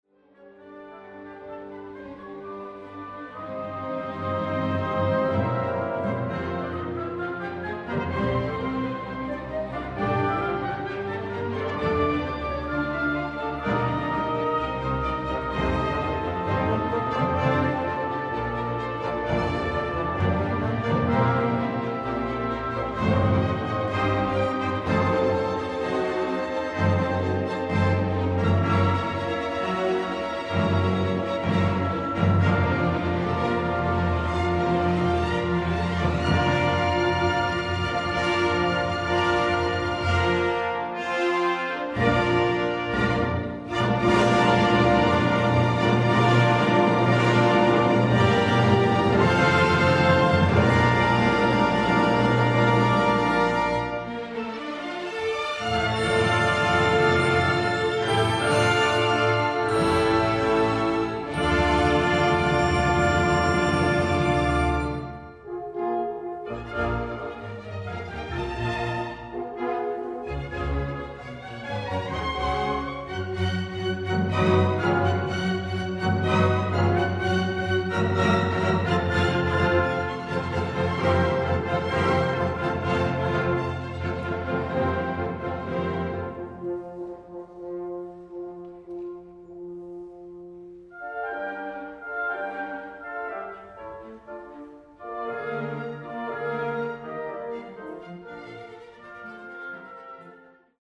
Programa 6 - OSY
LugarPalacio de la Música